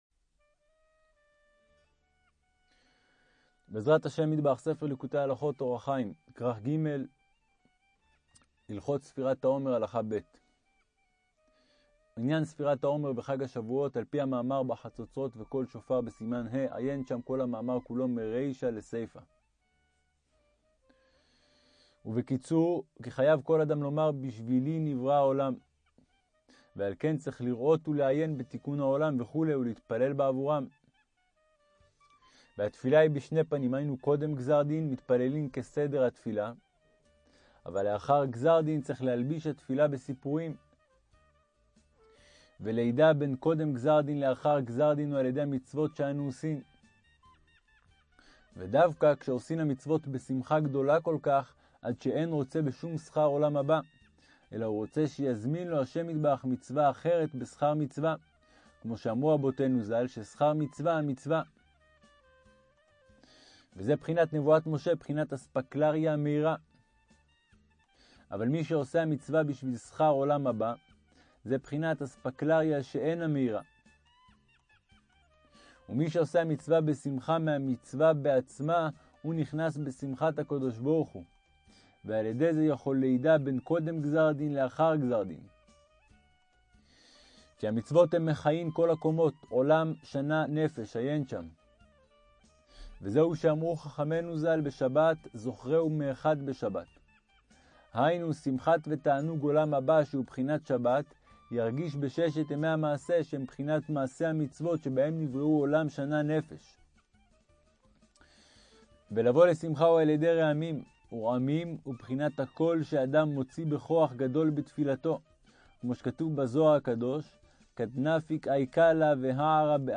ליקוטי-הלכות-אורח-חיים-ג-040-ספירת-העומר-ב-ניגונים.mp3